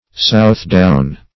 Southdown \South"down`\, n.